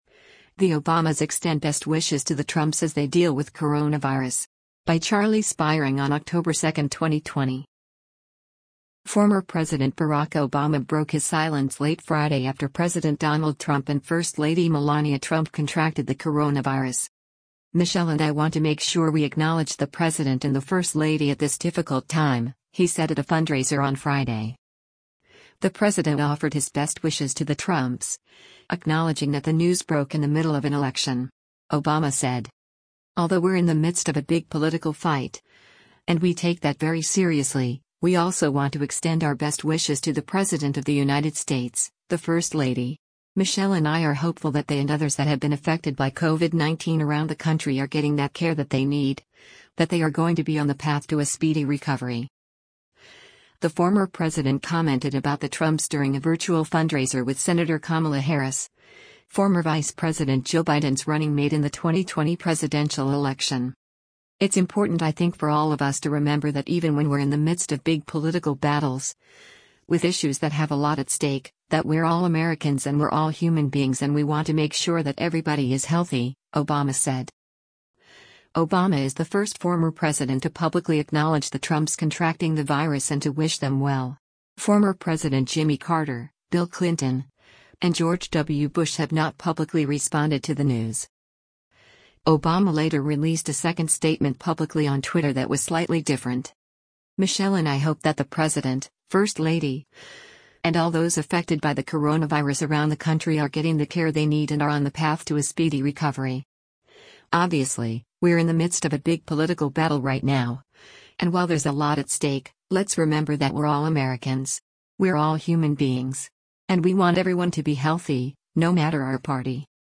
The former president commented about the Trumps during a virtual fundraiser with Sen. Kamala Harris, former Vice President Joe Biden’s running mate in the 2020 presidential election.